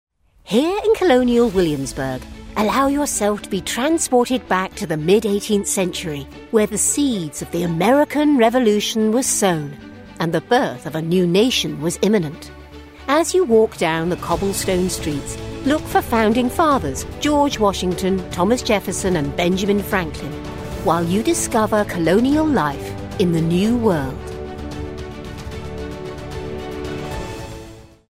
An experienced British English voice actor with a warm assured voice and versatility
Tour Guide Narration